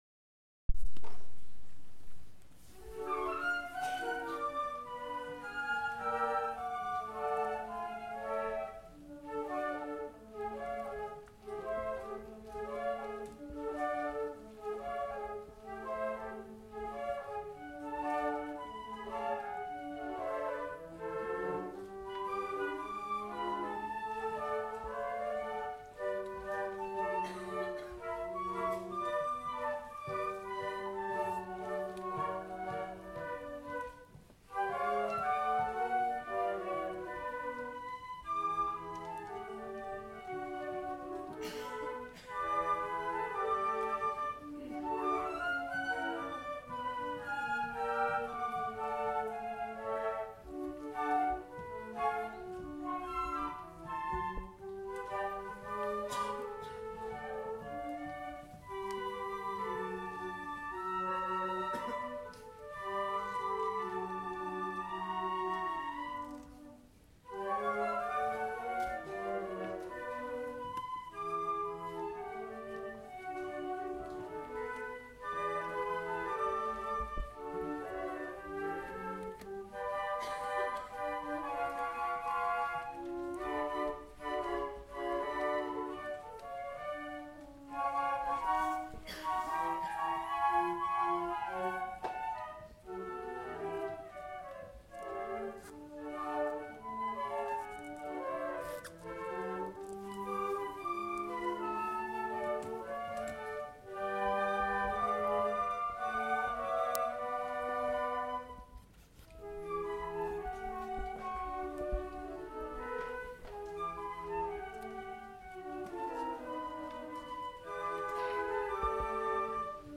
flute choir